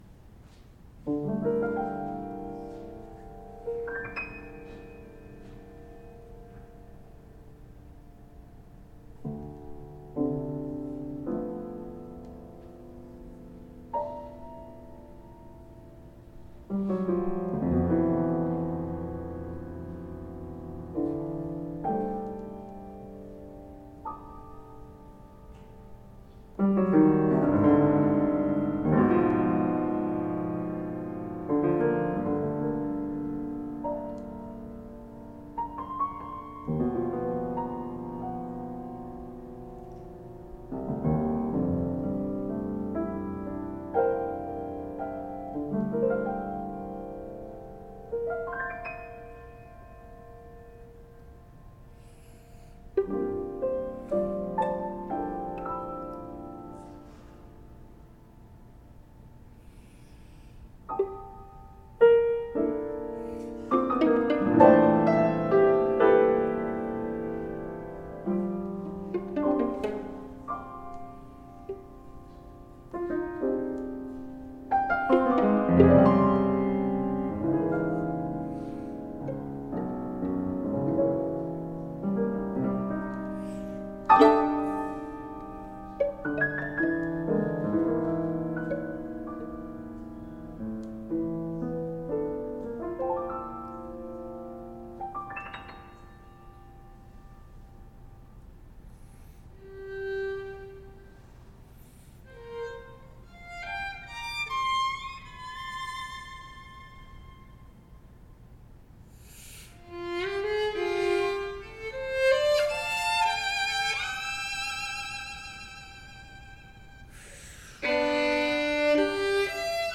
Each instrument is a physical representation of the concepts. The piece opens with piano, which represents a dark and dissonant reality. The violin emerges, bringing a fantastical element and settling into a dream-like state. In the end, reality creeps back in as fantasy fades. However, reality also begins to fade until stillness is brought about, and there is truly nothing but oblivion.